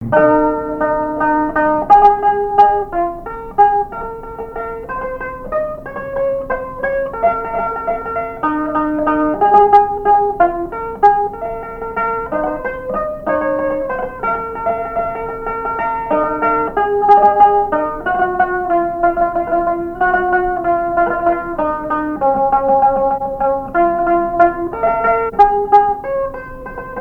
Beauvoir-sur-Mer
danse : valse
répertoire au violon et à la mandoline
Pièce musicale inédite